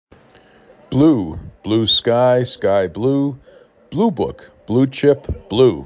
4 Letters, 1 Syllable
3 Phonemes
b l u